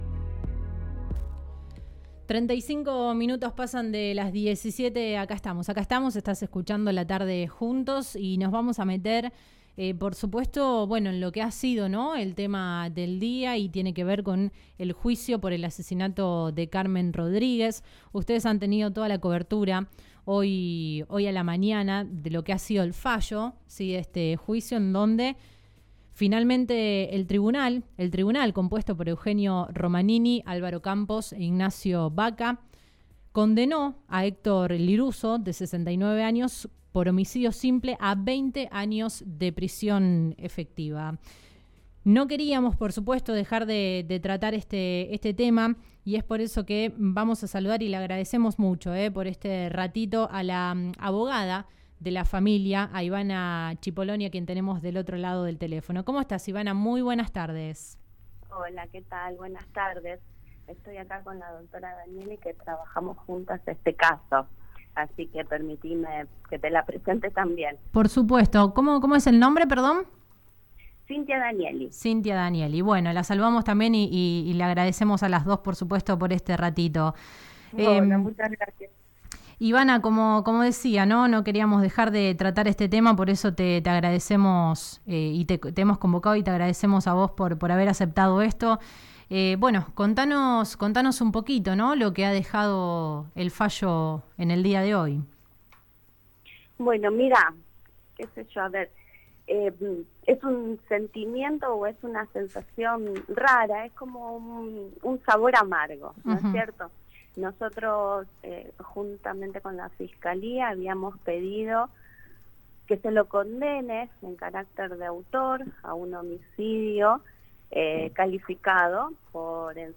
En diálogo con el programa radial La Tarde Juntos de Radio Del Sur FM 107.3